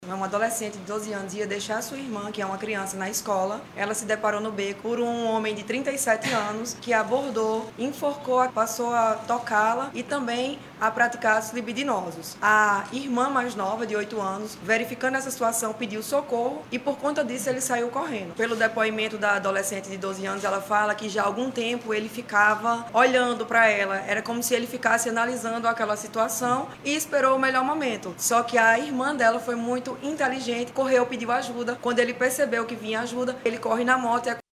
Em coletiva de imprensa